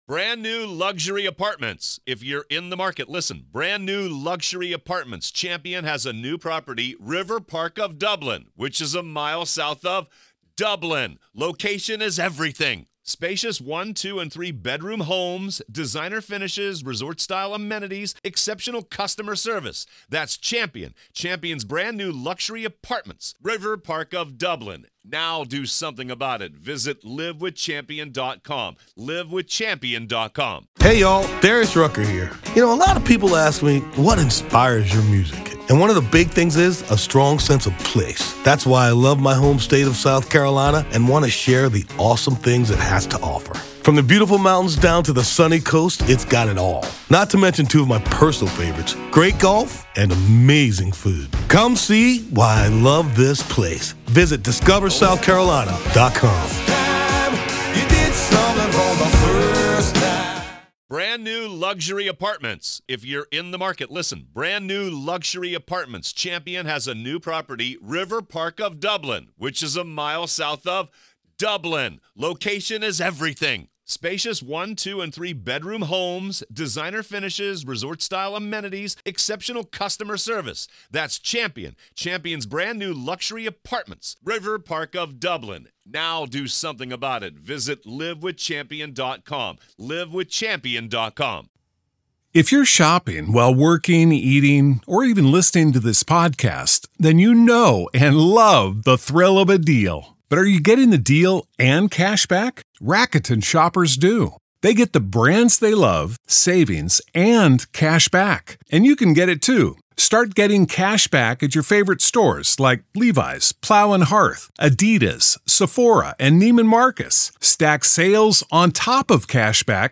The Trial Of Alex Murdaugh | FULL TRIAL COVERAGE Day 11 - Part 4